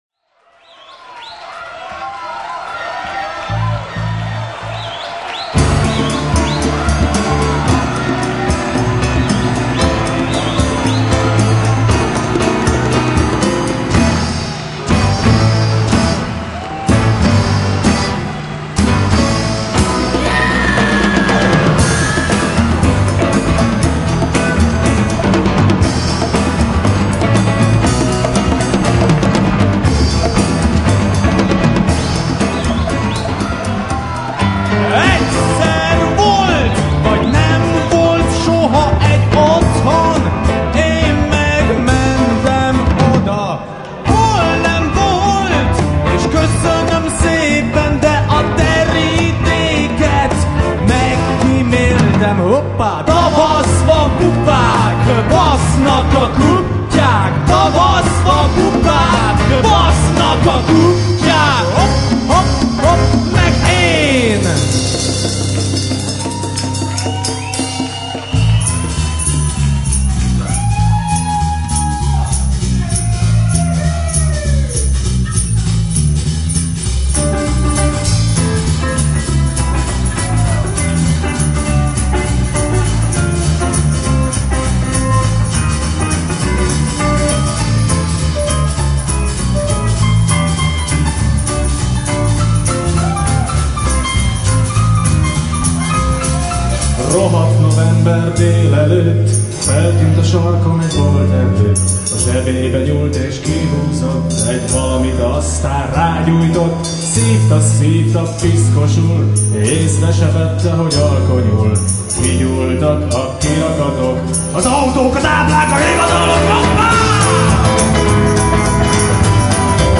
Innen mp3-ban letölthető a koncert néhány részlete.